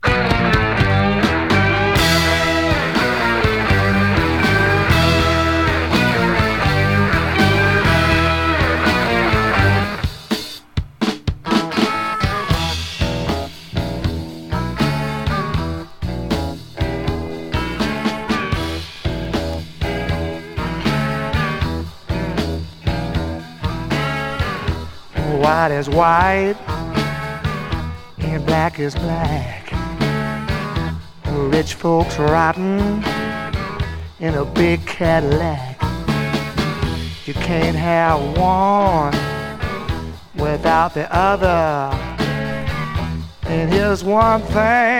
Rock, Country Rock, Blues Rock　USA　12inchレコード　33rpm　Stereo